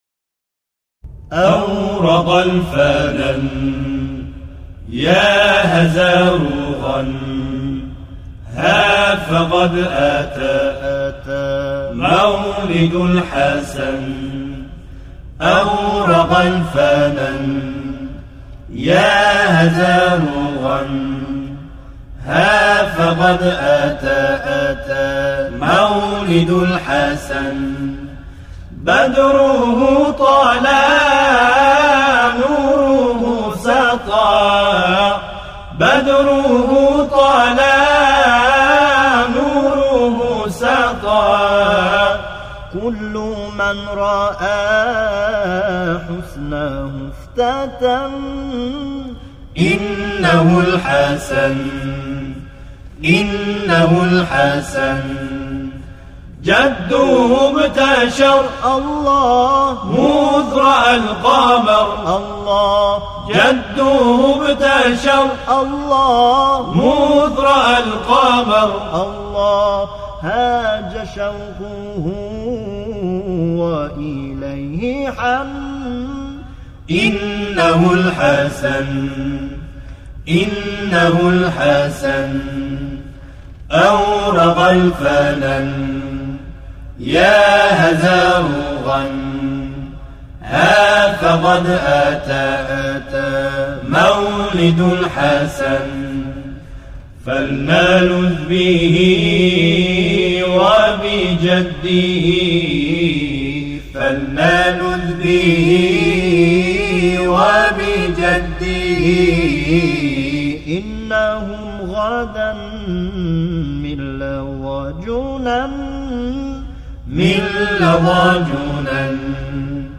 گروه هم‌خوانی